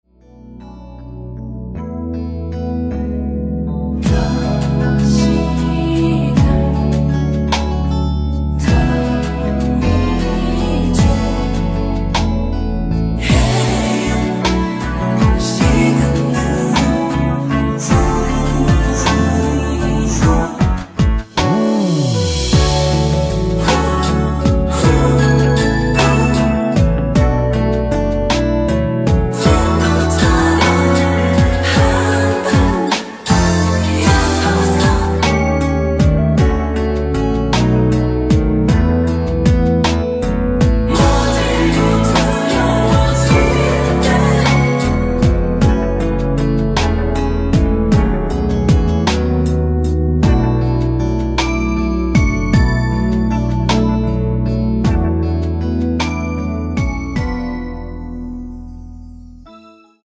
코러스 포함된 MR 입니다.(미리듣기 참조)
◈ 곡명 옆 (-1)은 반음 내림, (+1)은 반음 올림 입니다.
앞부분30초, 뒷부분30초씩 편집해서 올려 드리고 있습니다.
중간에 음이 끈어지고 다시 나오는 이유는